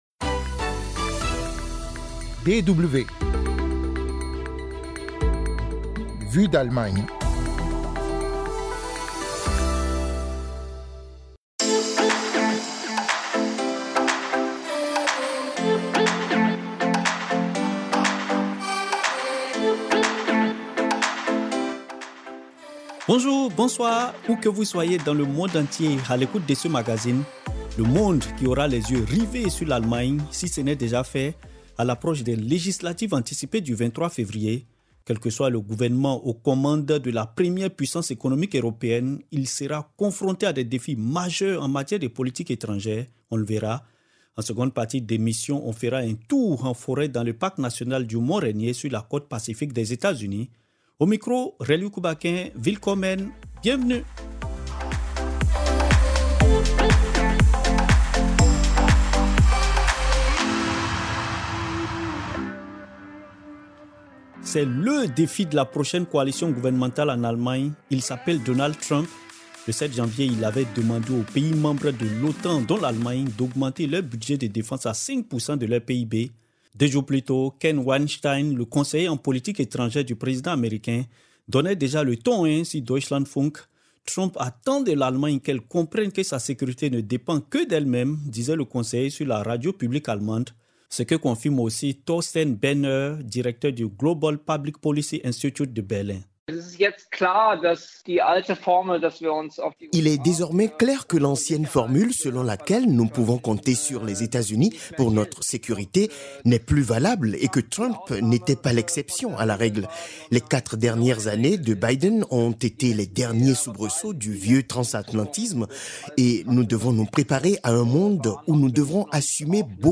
Politique, économie, histoire... Vu d'Allemagne est un podcast hebdomadaire sur l'Allemagne, chaque mercredi, avec un grand reportage international en seconde partie d'émission.